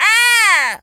crow_raven_squawk_06.wav